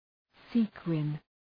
Shkrimi fonetik {‘si:kwın}